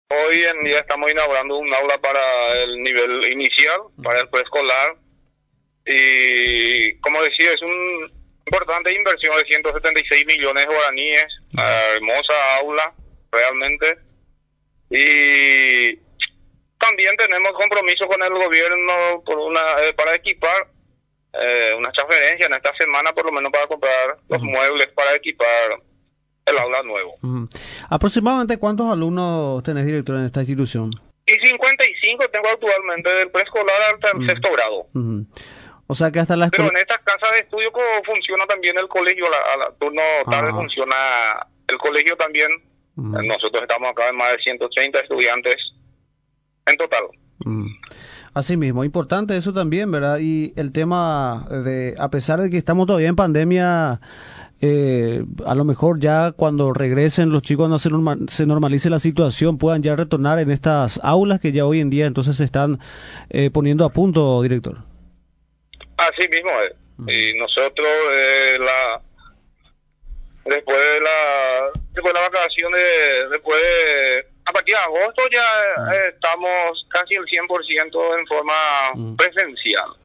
director de LA casa de estudios en conversación con Radio Nacional San Pedro, valoró la importancia de la inversión realizada para el confort de los niños de la comunidad educativa.